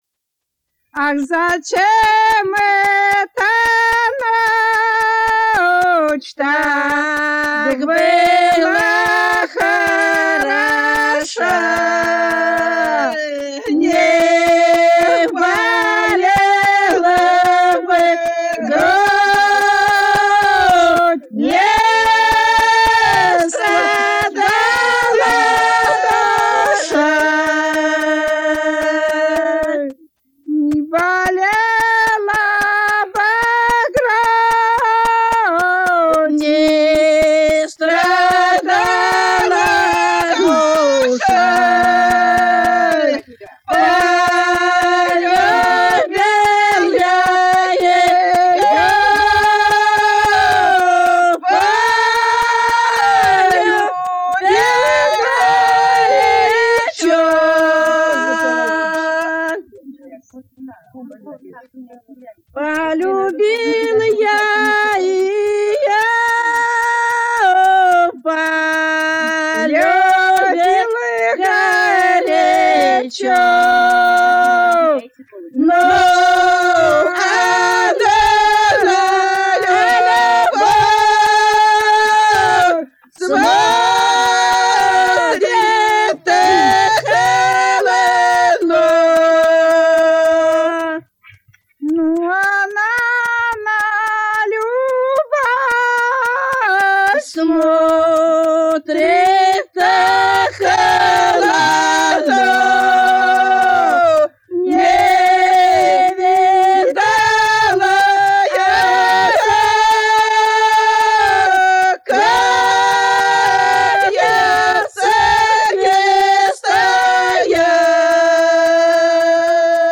Народные песни Касимовского района Рязанской области «Ах, зачем эта ночь», романс.